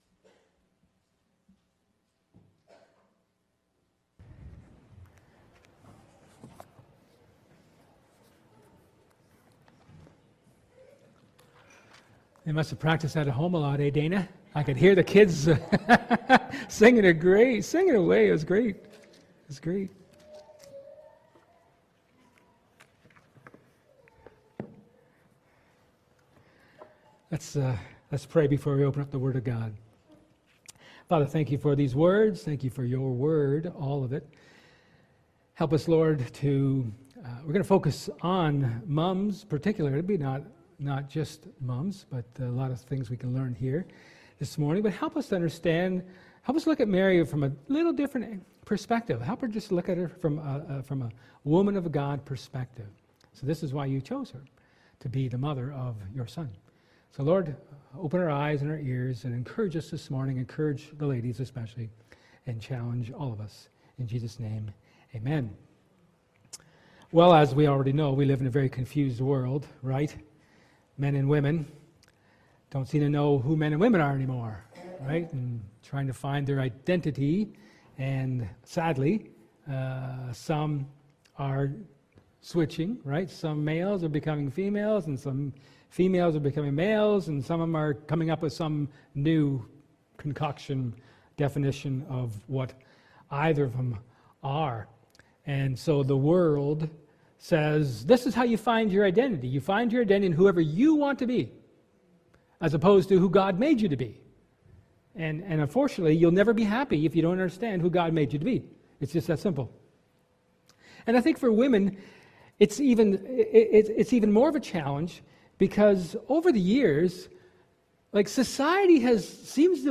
1 Corinthians 15:35-49 Service Type: Sermon